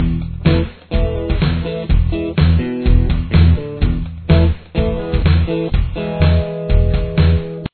Main Riff